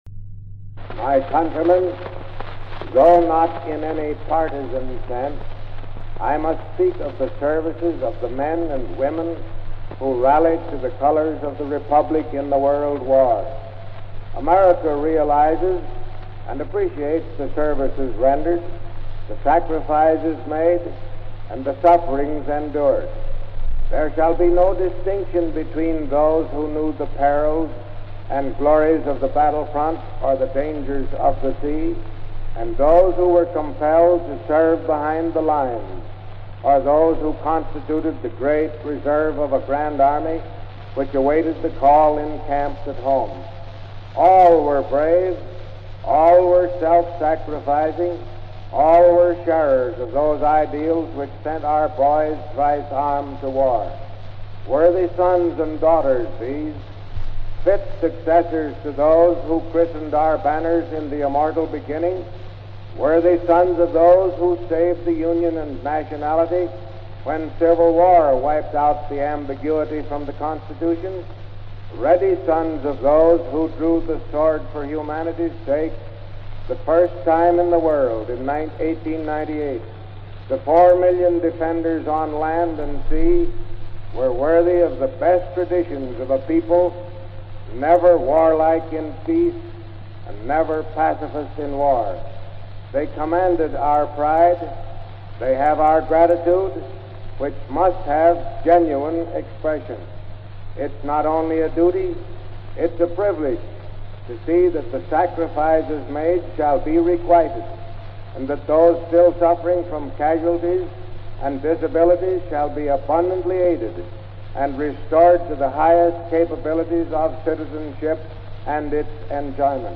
Presidential Speeches | Warren G. Harding Presidency July 22, 1920: The American Soldier